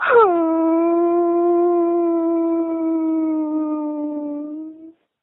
hnnn.mp3